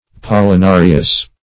Pollenarious \Pol`len*a"ri*ous\, a. Consisting of meal or pollen.